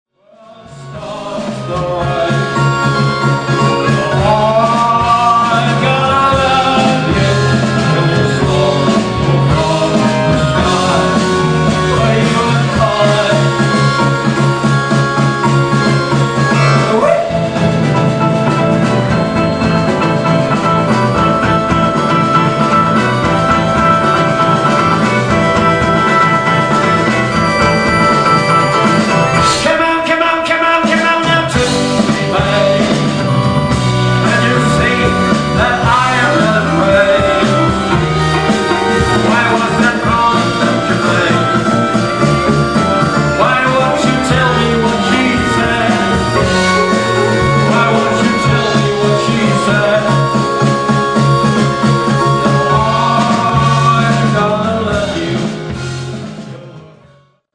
absolutely live mp3-Soundfiles